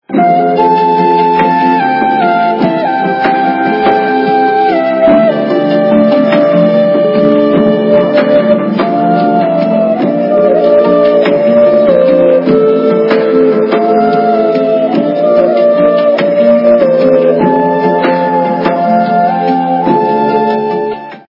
Из фильмов и телепередач
качество понижено и присутствуют гудки.